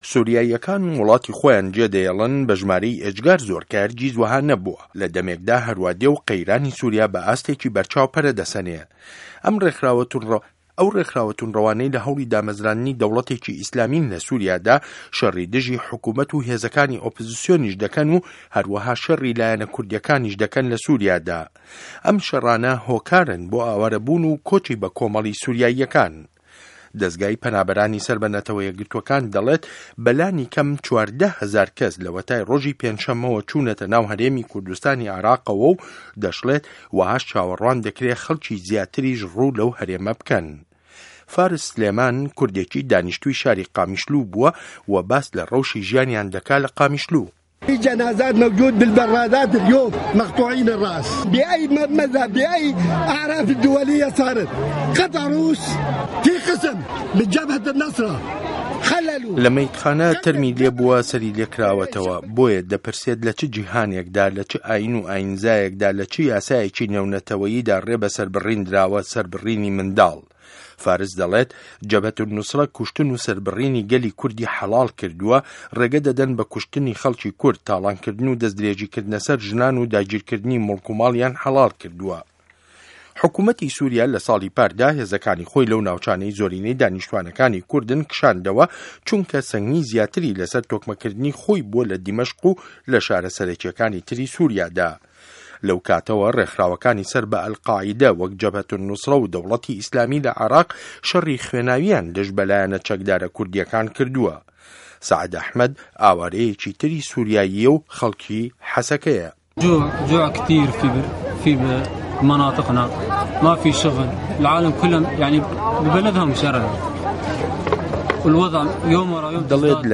ڕاپۆرتی بۆ کوردی سوریا زێدی خۆی جێده‌هێڵێت